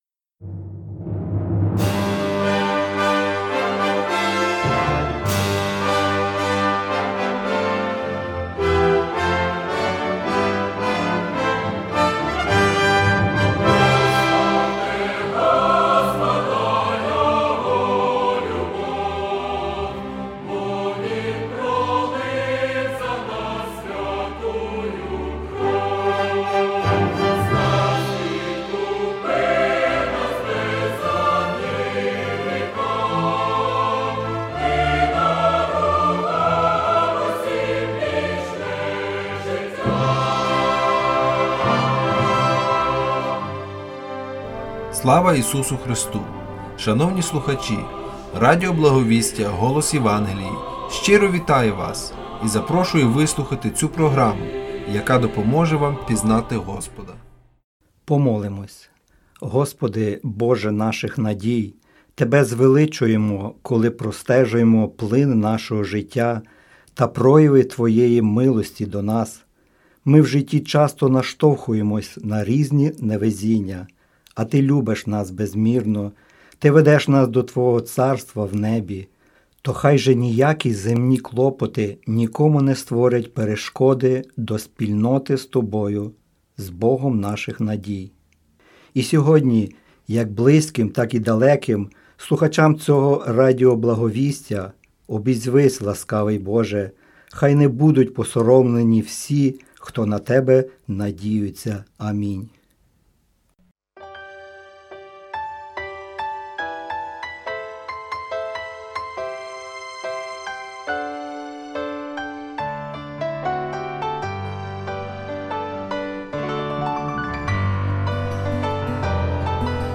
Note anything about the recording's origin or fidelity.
Tune in every Saturday for a new Ukrainian program featuring news, music, community events, commentary, historical and cultural insights.